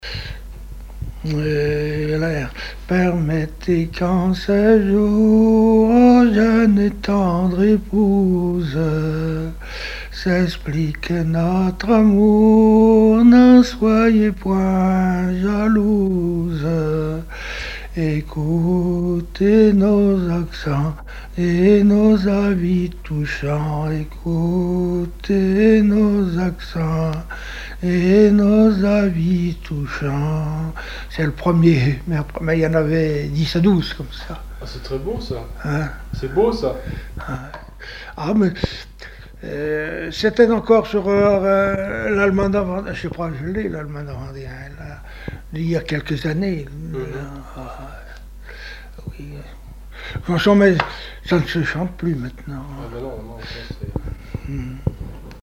circonstance : fiançaille, noce
Genre strophique
Répertoire instrumental à l'accordéon diatonique